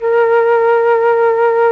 RED.FLUT1 16.wav